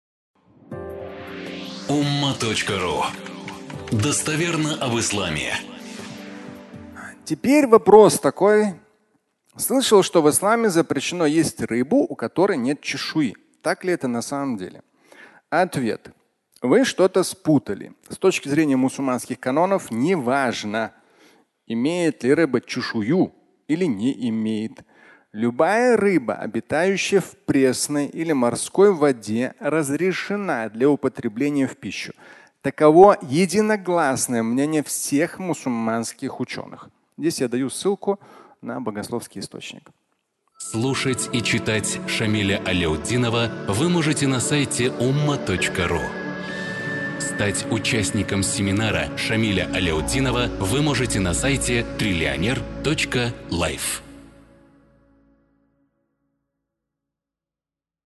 Без чешуи (аудиолекция)